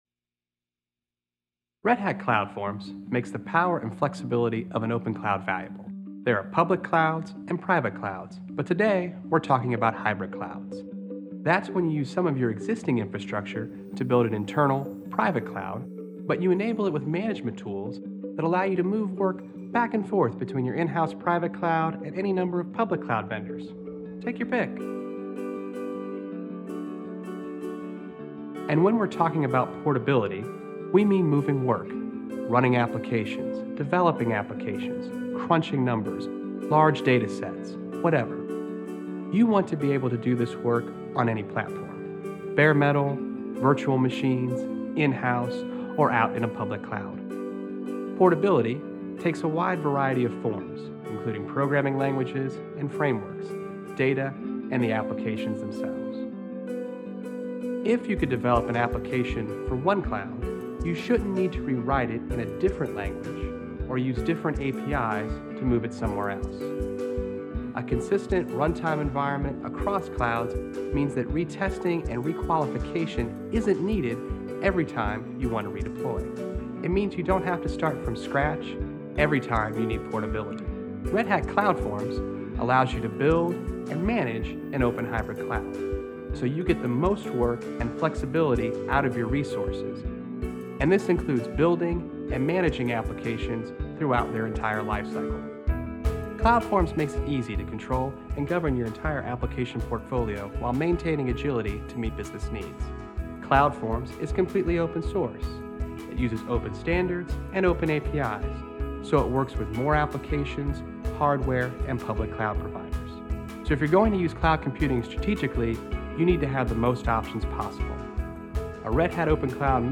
Recorded during a lunch session (apologies for clinking plates / glasses in the recording) I've also had to try and mix / amplify / blend in questions from the room as best I can.